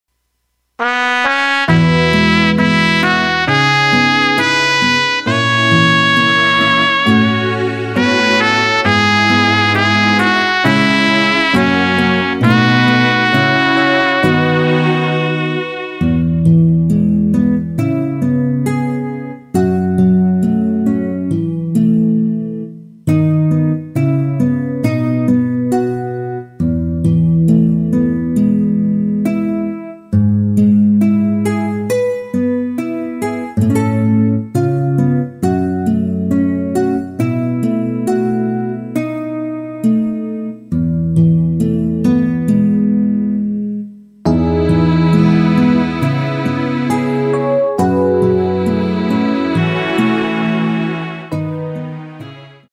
MR입니다. 발매일 1999.01. 키 E 가수
원곡의 보컬 목소리를 MR에 약하게 넣어서 제작한 MR이며